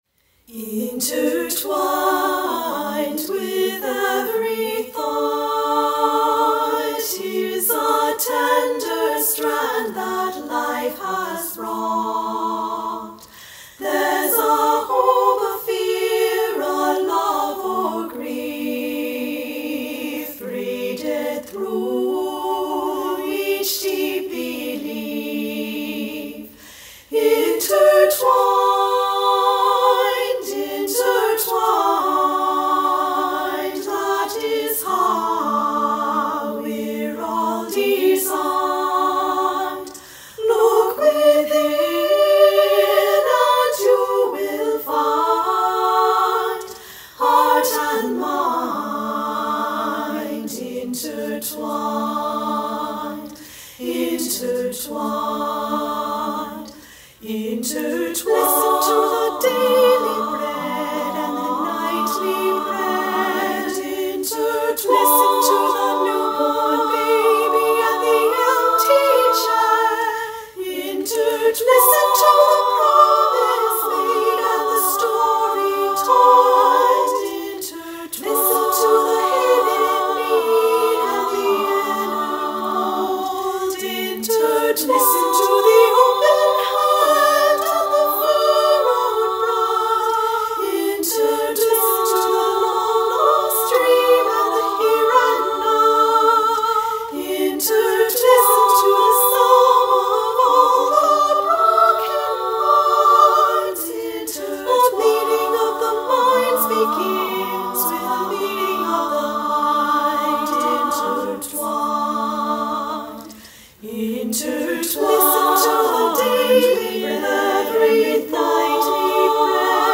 SSA or 2-3 pt choir, a cappella